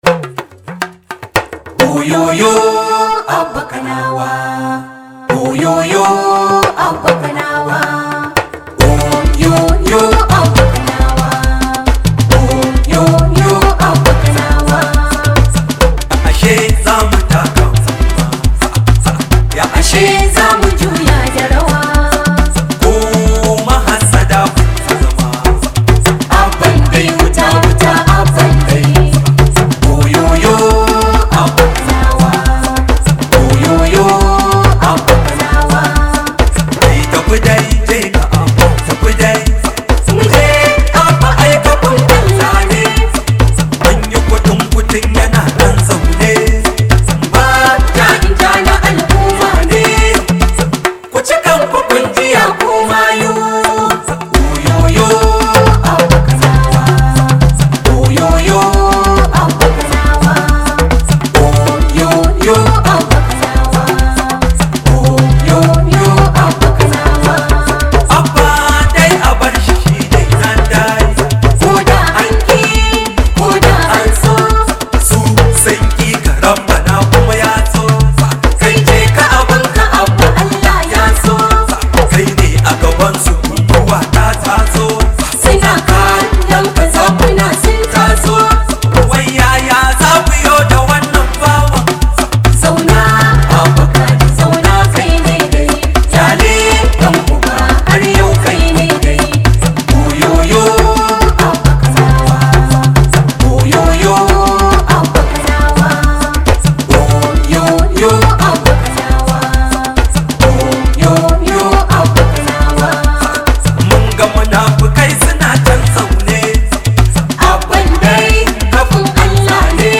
Hausa Musics
Shahararren mawakin nan na siyasa da ke jihar kano